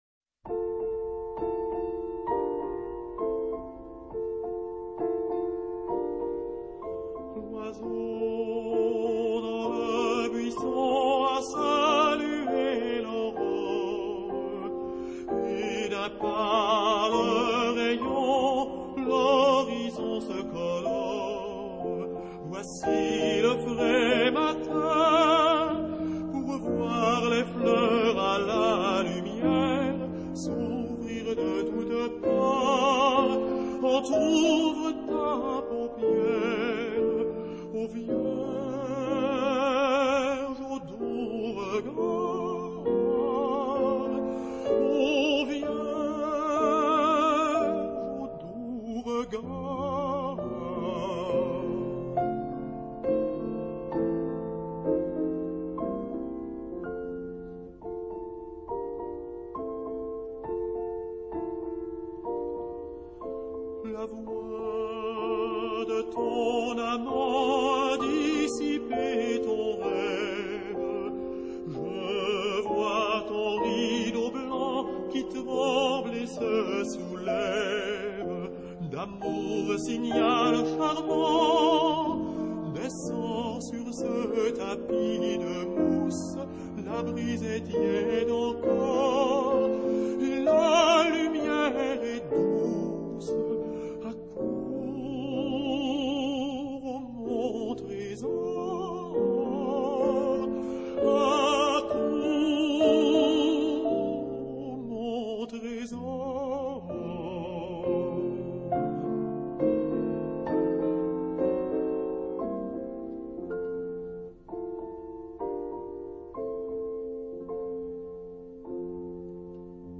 他的演唱很有溫度與情感。